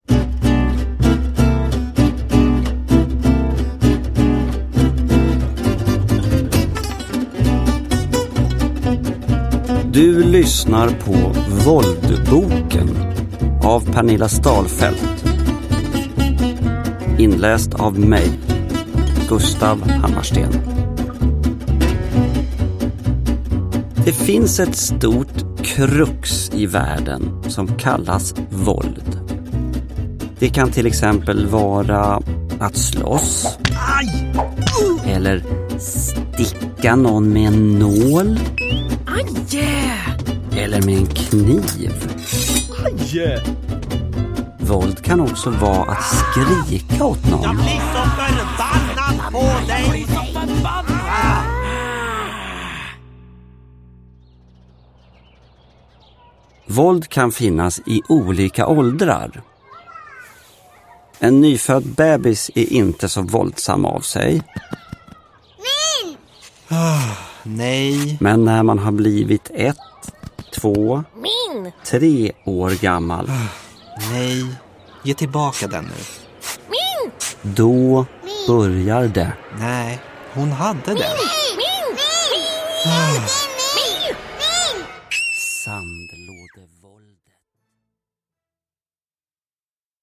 Våldboken – Ljudbok – Laddas ner
Uppläsare: Johan Ulveson